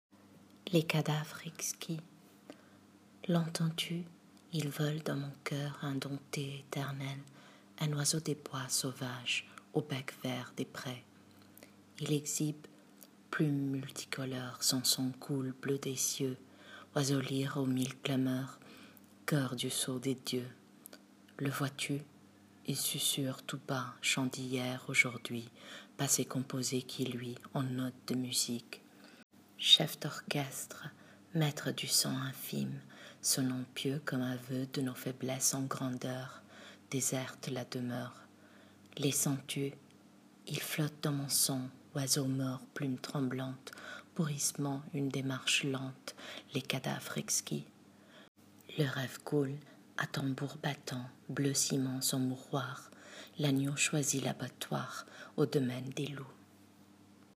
Lecture du poème: